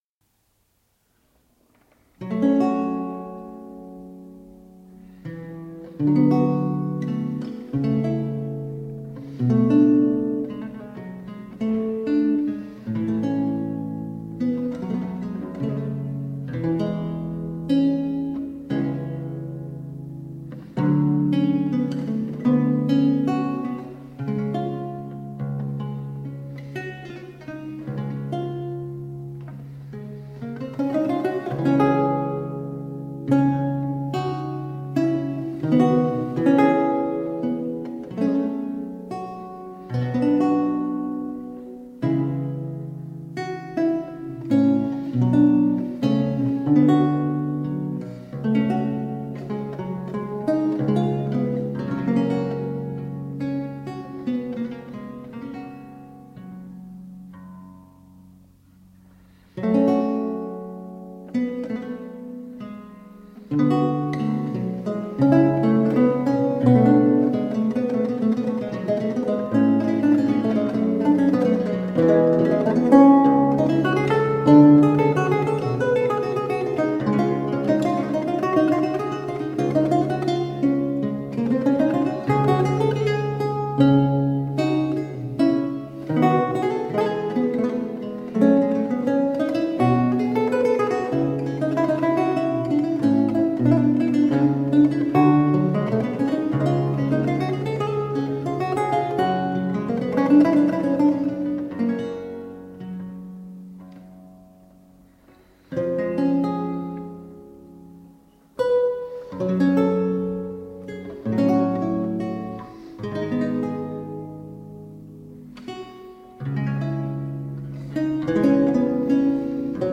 Reflective, historically-informed performance on the lute.
Classical, Renaissance, Baroque, Instrumental